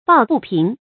抱不平 bào bù píng
抱不平发音